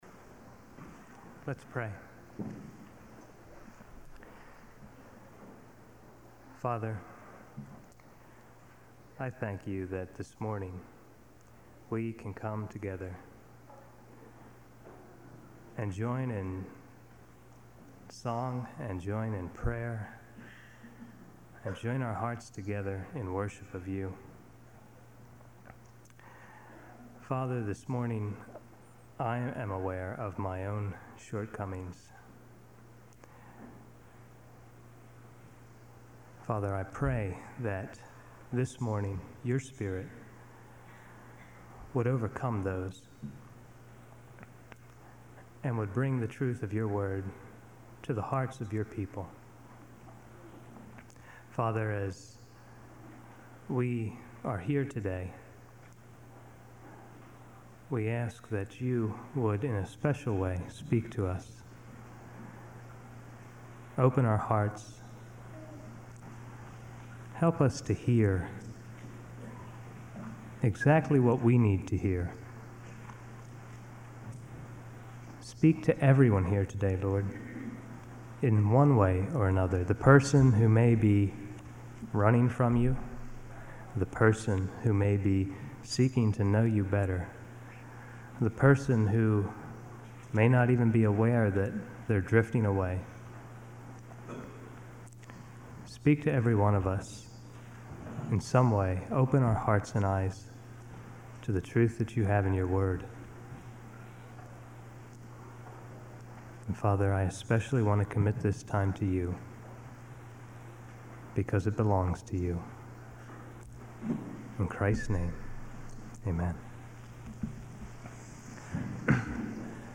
Audio Sermons — Brick Lane Community Church